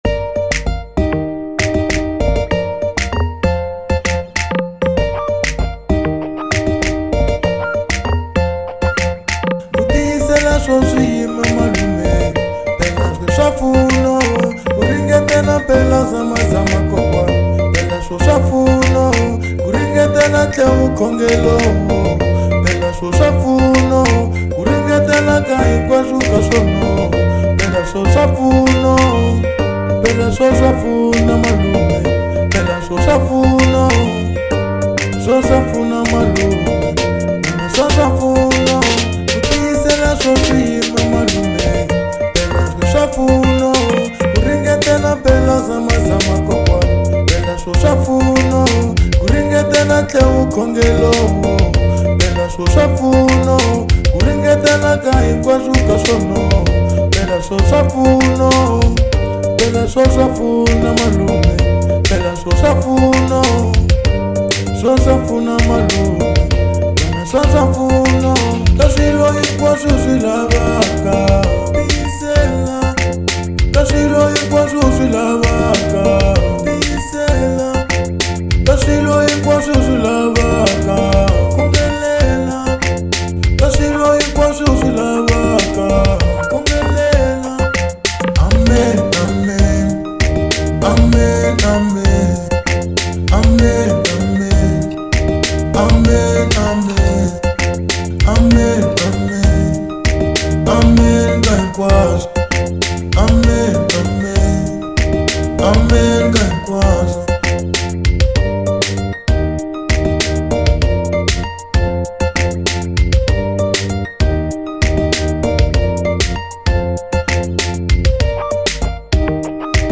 04:11 Genre : Gqom Size